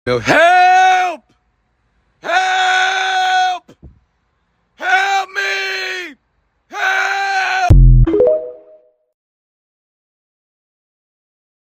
Man Screaming Help Help Help Me